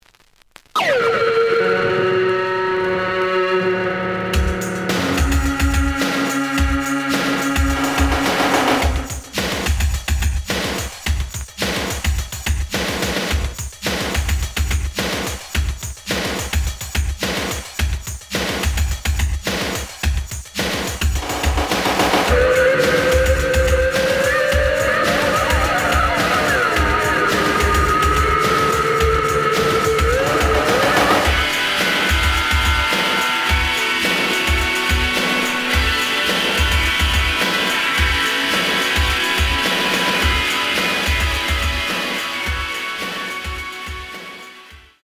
The audio sample is recorded from the actual item.
●Genre: House / Techno